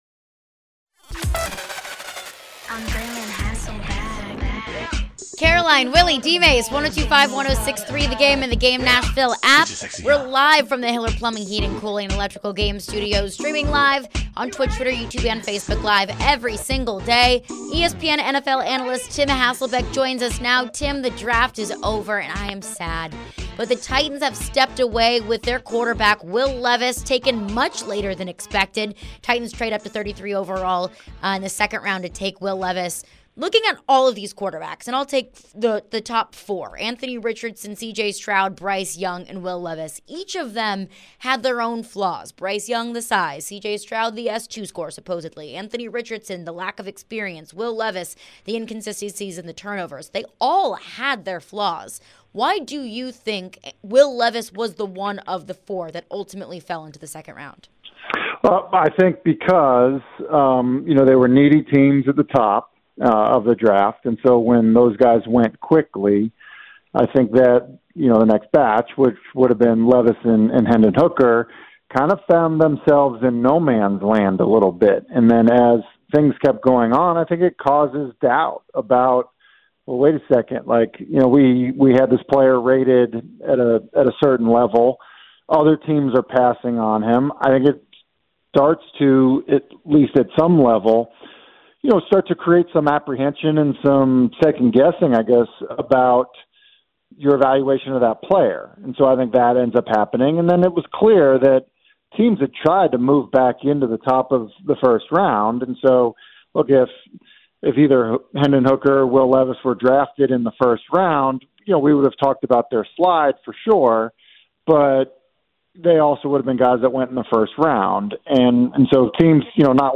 Tim Hasselbeck Interview (5-2-23)
ESPN NFL Analyst Tim Hasselbeck joined the show discussing Will Levis & more headlines around the NFL.